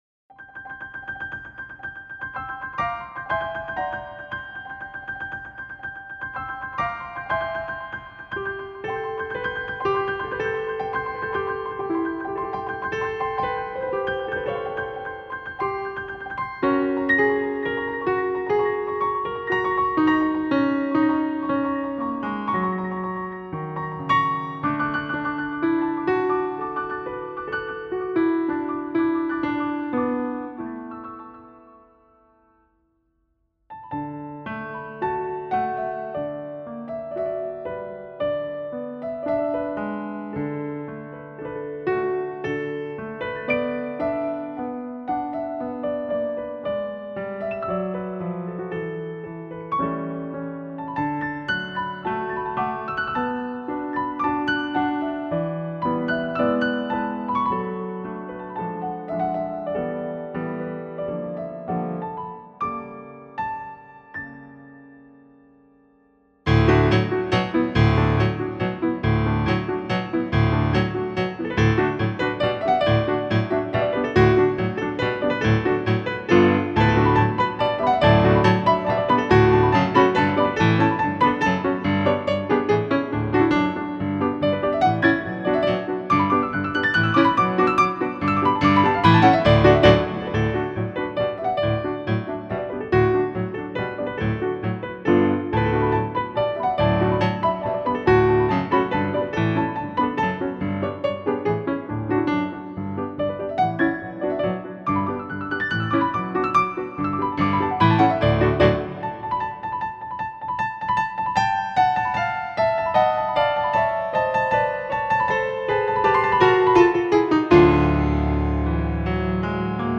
Фортепианная сюита